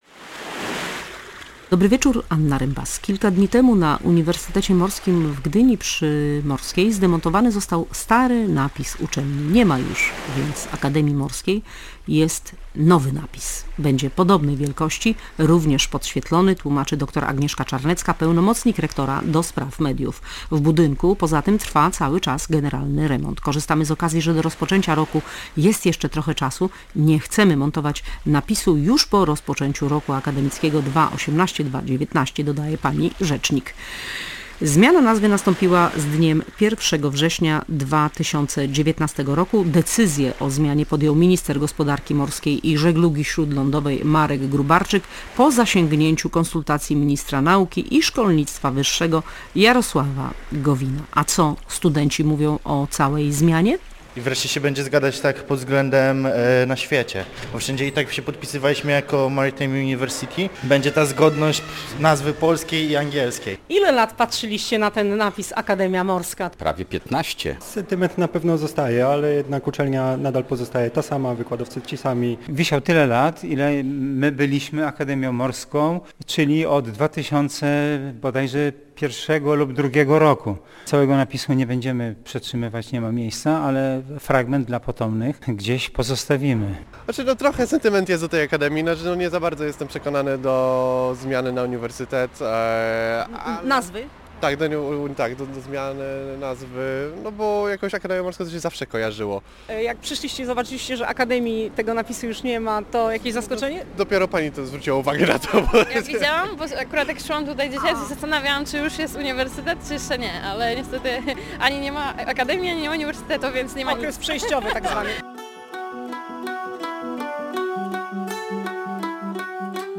Zmiana napisu Akademia Morska na Uniwersytet Morski, co słychać u kapitan Joanny Pajkowskiej płynącej dookoła świata i rozmowa z dyrektorem